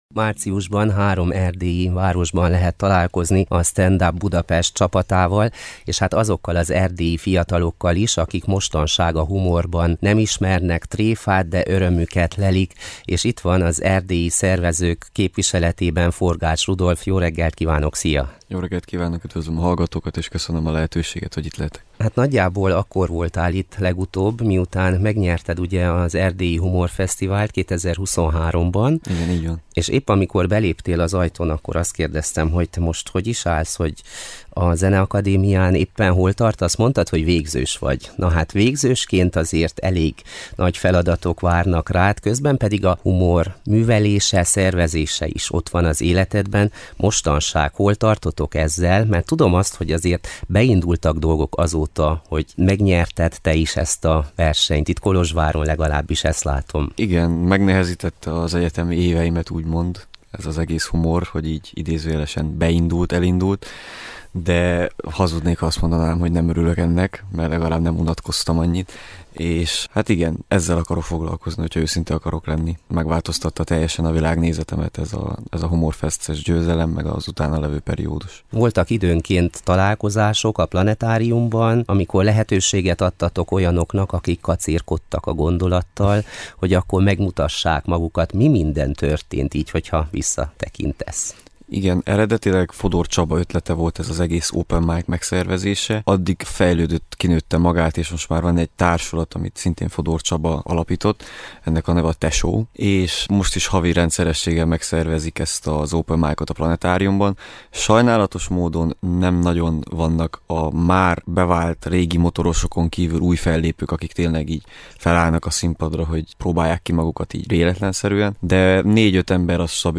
Beszélgetőtárs